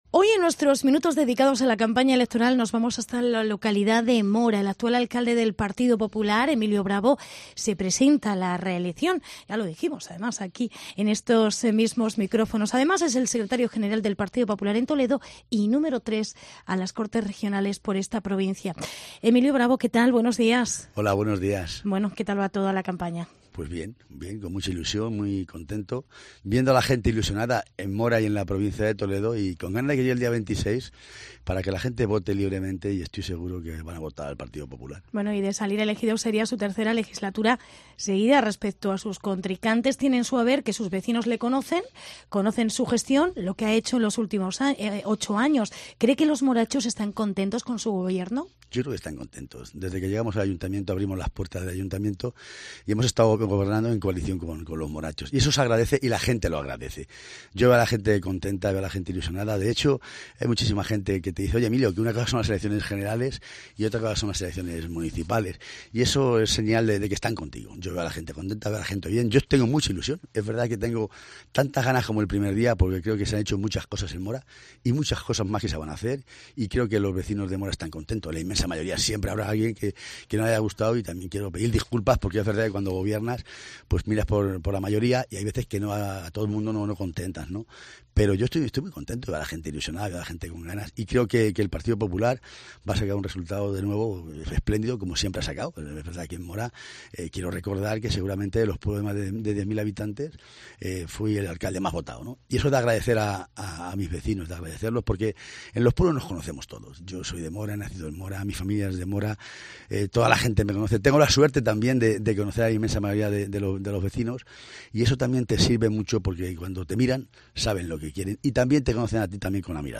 Entrevista con Emilio Bravo. Candidato a la reelección a la alcaldía de Mora